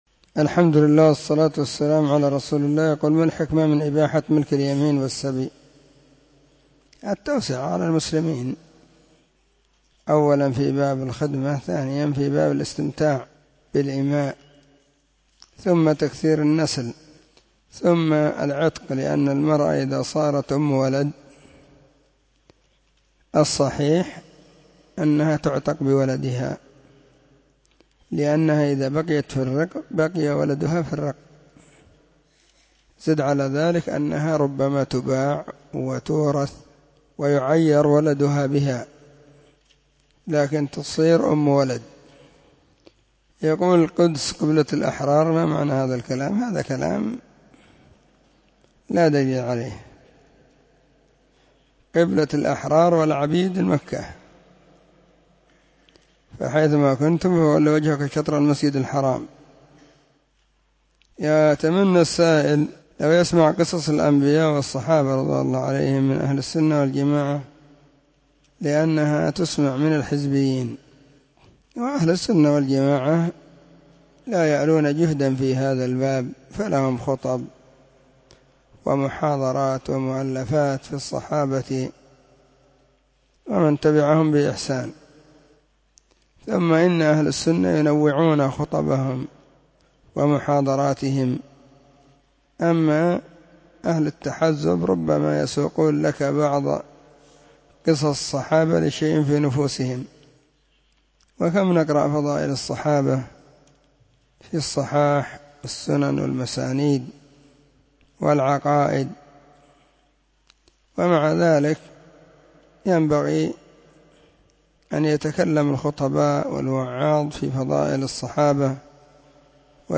فتاوى الإثنين 17 /ربيع الثاني/ 1443 هجرية. ⭕ أسئلة ⭕ -13